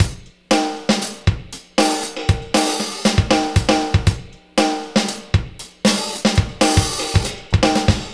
Gliss 2fer 3 Drumz.wav